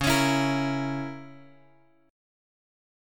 C#m6 chord {x 4 x 6 5 6} chord
Csharp-Minor 6th-Csharp-x,4,x,6,5,6.m4a